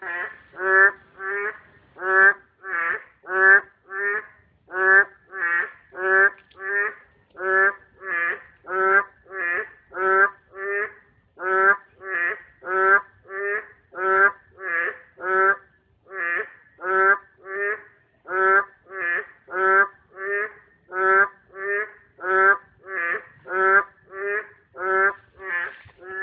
어은중학교에서 맹꽁이가 울기 시작했습니다~~
그 특유의 울음소리 때문에 맹꽁이가 있다는 것을 알 수 있습니다.
한마리가 ‘맹’ 하면 다른 한마리가 ‘꽁’하는 소리~
첨부파일에 어은중학교에서 녹음한 맹꽁이 소리를 올려놨습니다.